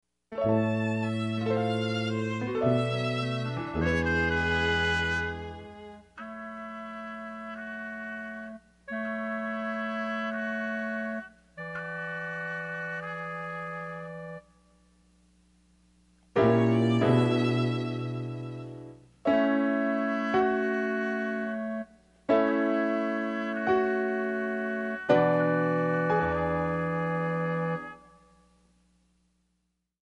Buy With Backing Vocals.
Gb/G/Ab
MPEG 1 Layer 3 (Stereo)
Backing track Karaoke
Musical/Film/TV, Jazz/Big Band, 1970s